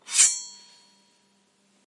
厨房的声音 " 在咖啡机的金属磨盘上拖动/刮擦陶瓷杯（剑声） 3
描述：陶瓷杯底部的声音，拖过咖啡机的金属格栅。这听起来像是从剑鞘中抽出的剑。 在厨房使用Zoom H6录制。应用滤波器去除不需要的低频和极高频率。
此外，背景中的风扇也非常微弱。
标签： 光栅 拖动 金属 陶瓷 咖啡
声道立体声